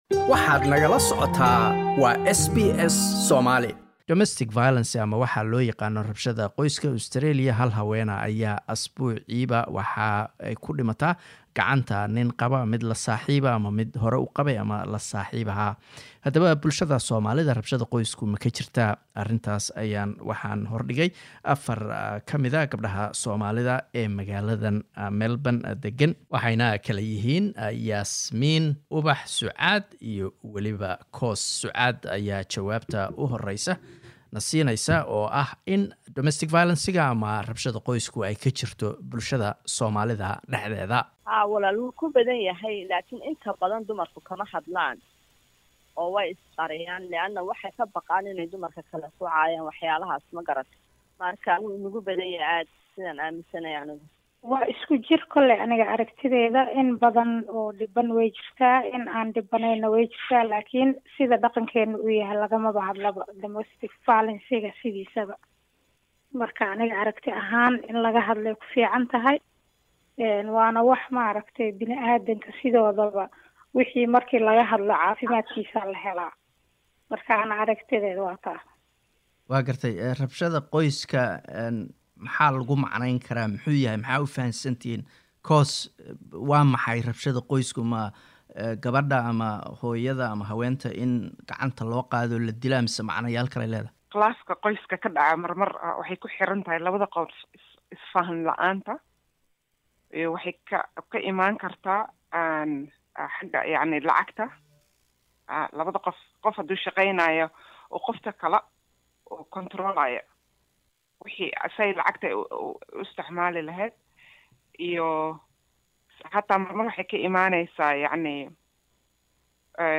Australia hal haween ah ayaa asbuuc walba waxaa dila nin ay xiriir la haayeen. Haddaba Soomalida ma ku badan tahay rabshada qoysku? waxaa aragtidooda nala wadaagay 4 gabdhood oo jaaliyadda ka tirsan.